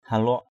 /ha-lʊaʔ/ 1.